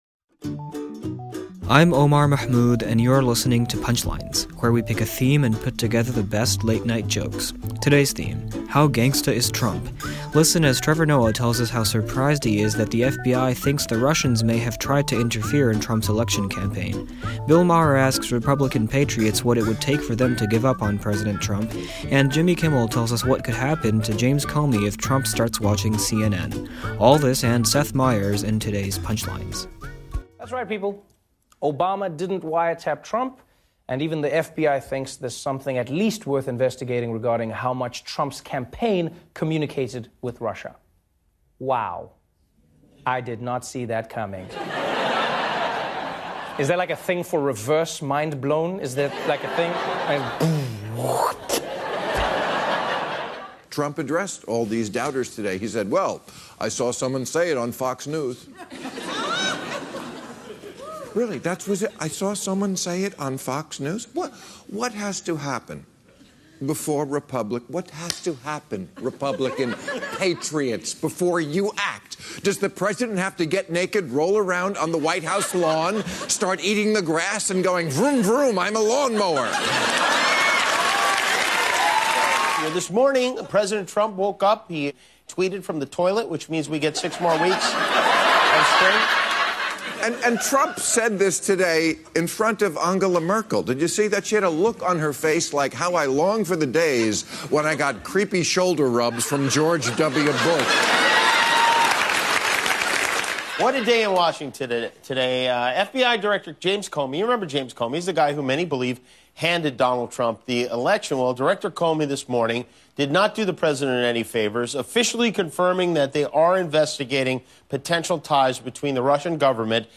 The late-night comics take a look at one of the president's latest tweets and recent FBI hearing.